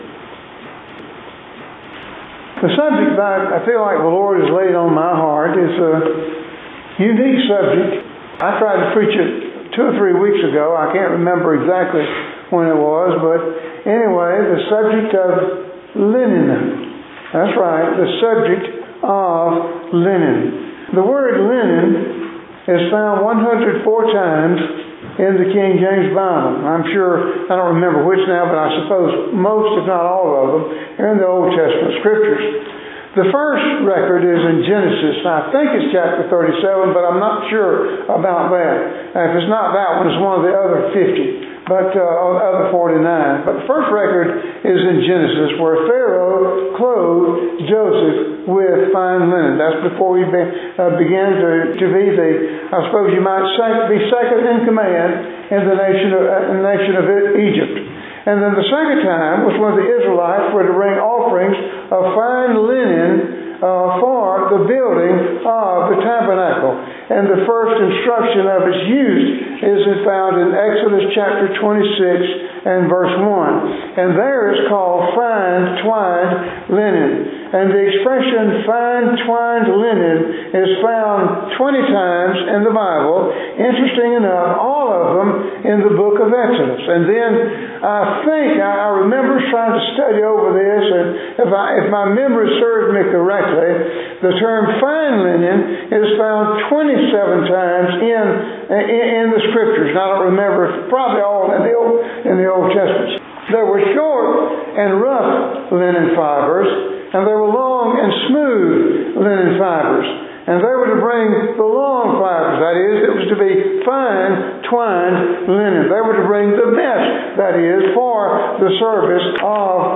Sermon by Speaker , A Gathering of Saints